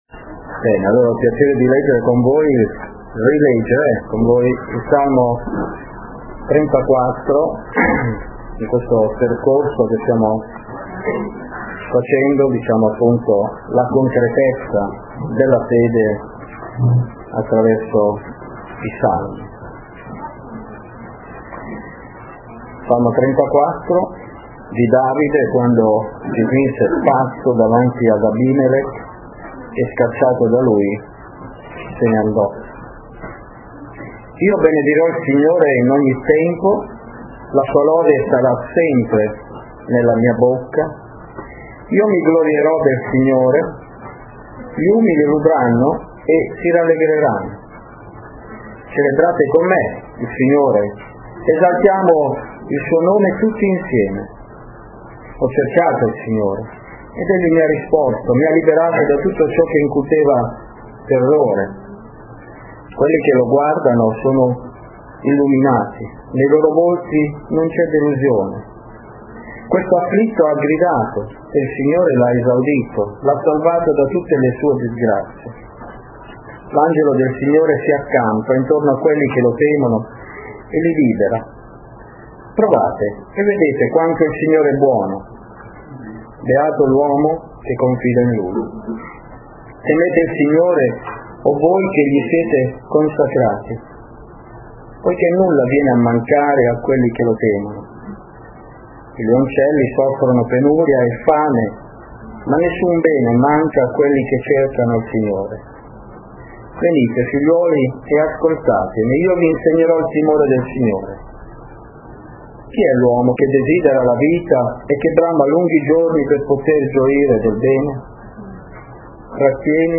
Predicatori